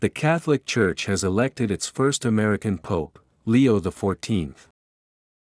⇒　弱音の機能語のリスニングはやや難易度が高いです。「elected its」とリンキングしている箇所に注意してください。